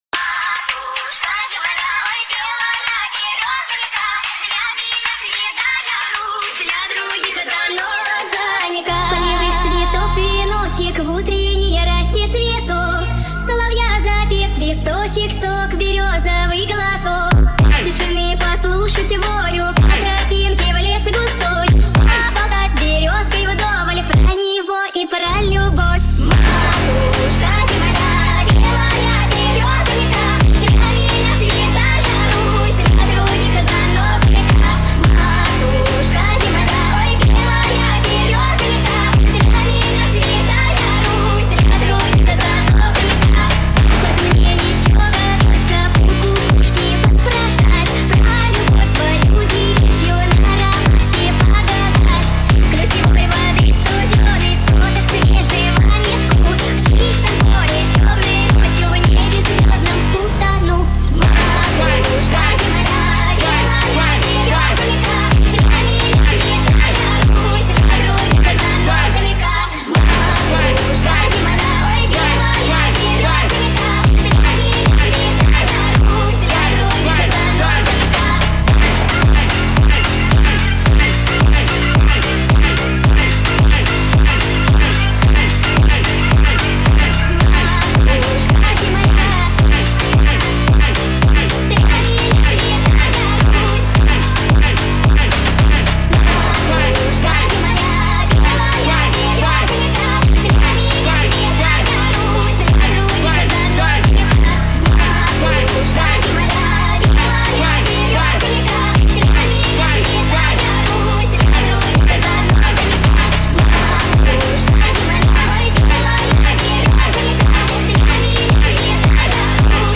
Brazilian Phonk, TikTok Remix Version 2025